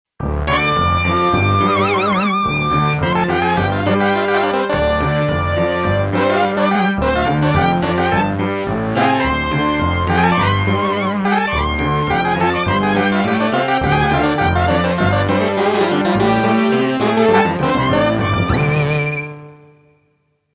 Class: synthesizer module
Synthesis: analog sub
factory demo 1